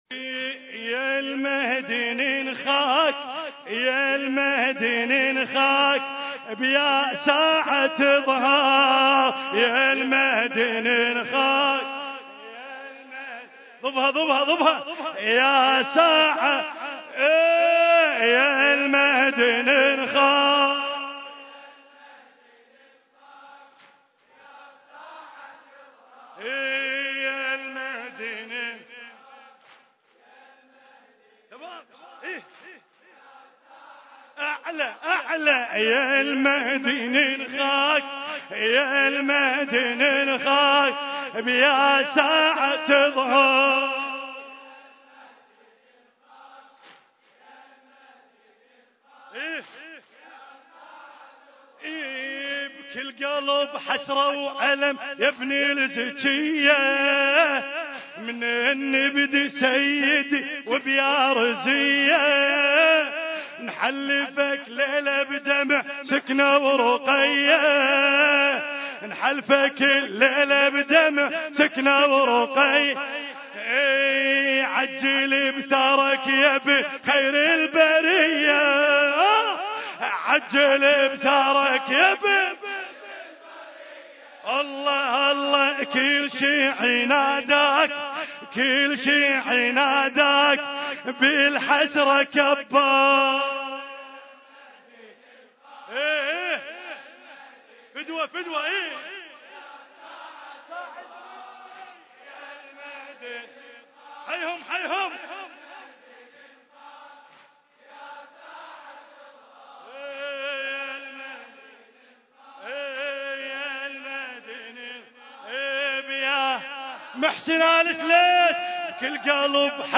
مجلس الغدير بتاروت حسينية كريم أهل البيت (عليهم السلام) ذكرى شهادة الإمام موسى الكاظم (عليه السلام)